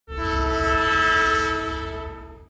دانلود آهنگ سوت قطار 2 از افکت صوتی حمل و نقل
دانلود صدای سوت قطار 2 از ساعد نیوز با لینک مستقیم و کیفیت بالا
جلوه های صوتی